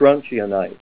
Say STRONTIANITE Help on Synonym: Synonym: ICSD 202793   PDF 5-418